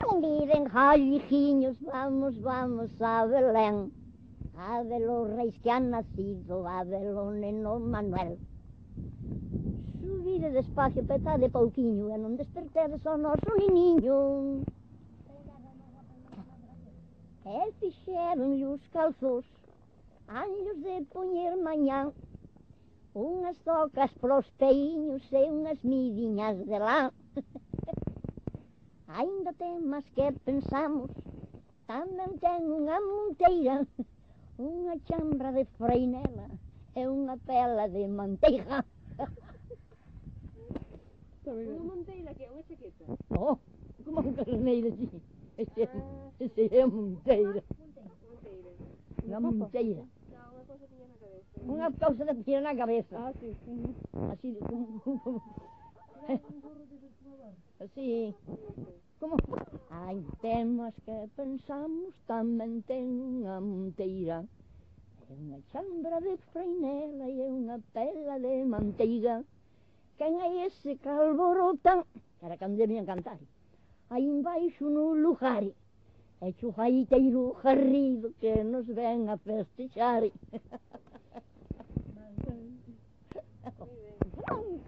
Tipo de rexistro: Musical
Soporte orixinal: Casete
Instrumentación: Voz
Instrumentos: Voz feminina
Audio editado con Audacity por presentar distorsións de voz.
Cancioneiro Popular Galego sobre a melodía: Vol. II Festas anuais. Melodías novas, con estructura melódica de acorde, con ámbito V-5 (III-5).